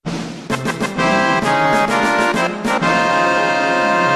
Victory Sound Effect Free Download